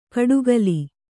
♪ kaḍugali